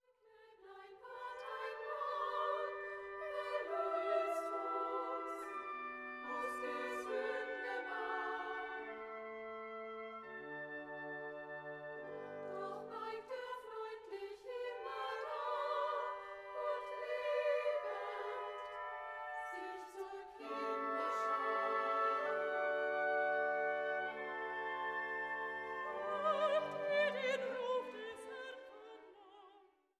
für Sopran, Bariton, Frauenchor und Orchester